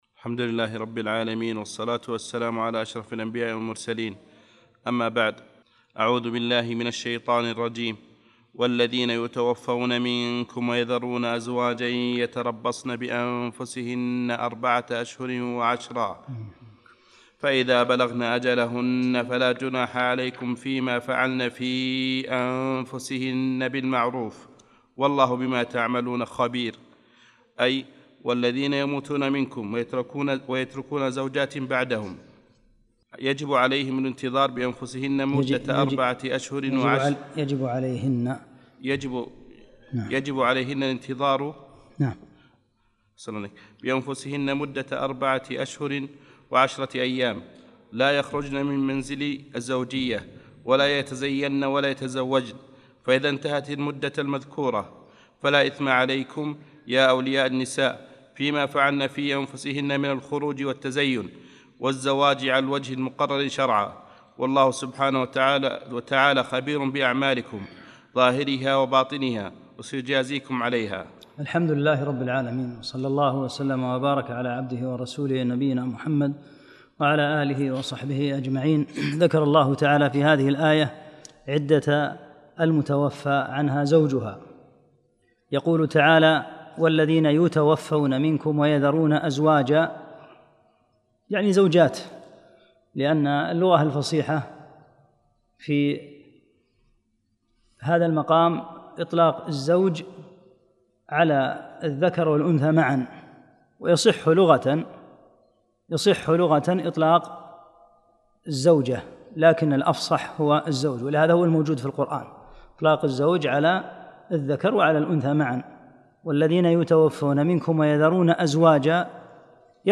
31 - الدرس الحادي والثلاثون